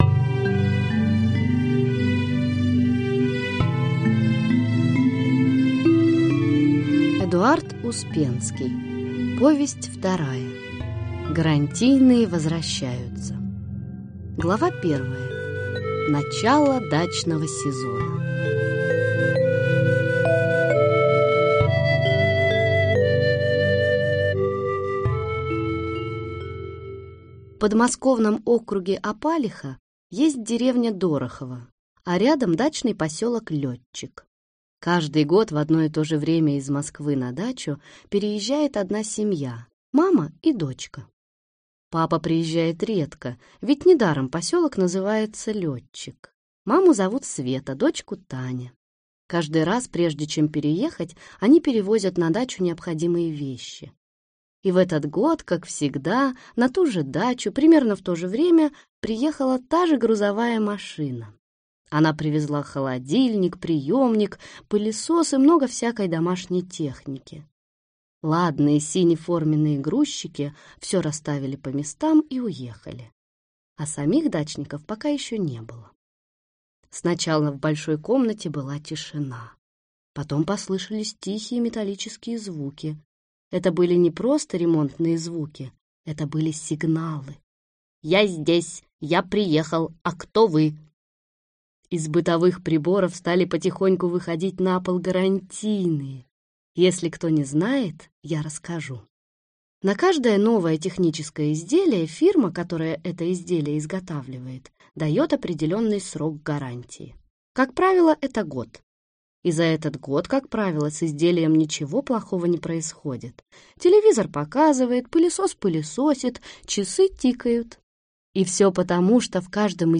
Аудиокнига Гарантийные человечки возвращаются | Библиотека аудиокниг
Прослушать и бесплатно скачать фрагмент аудиокниги